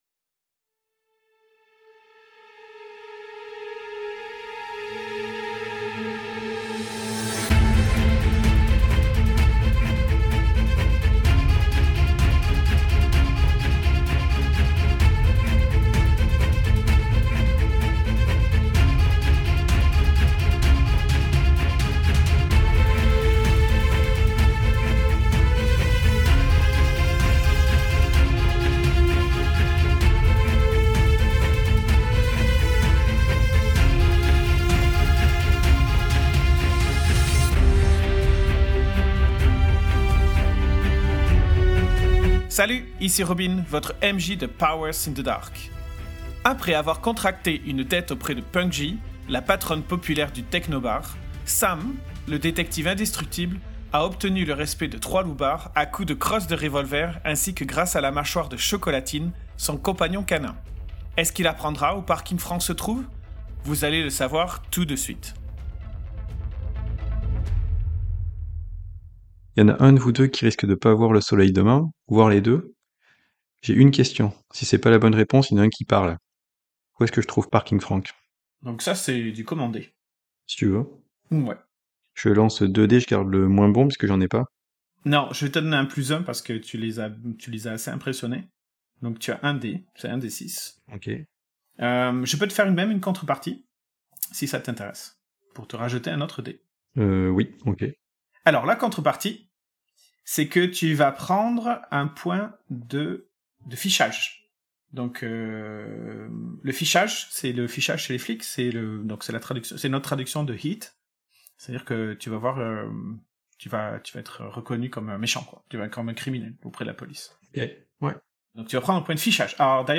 dog growls.wav
Bone Crunch Fast.wav
Fire alarm
Camera Shutter Click
9mm Gunshots 1
Synth-pop